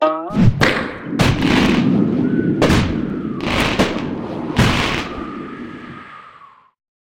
slingshot.ogg.mp3